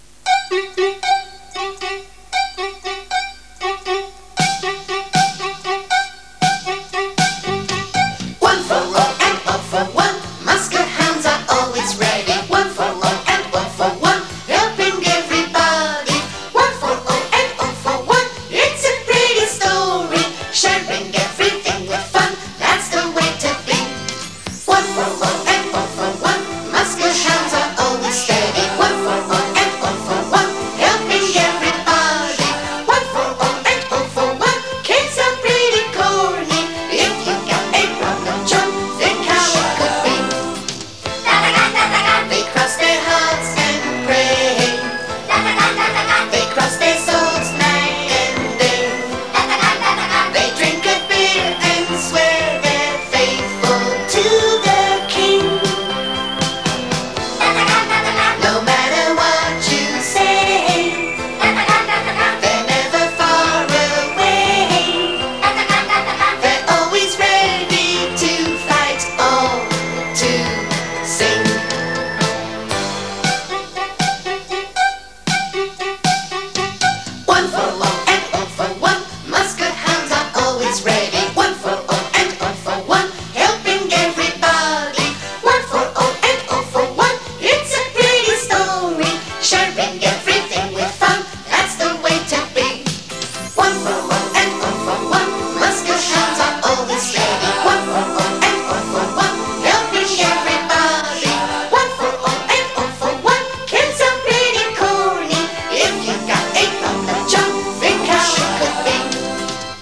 theme music